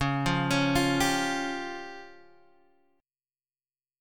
C#Mb5 chord